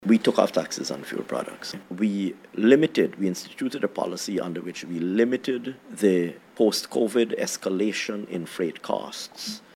In an interview on the Starting Point Podcast, the Minister noted that Guyana, like the rest of the world, is affected by global price increases, but also reflected on the measures that were rolled out by the government.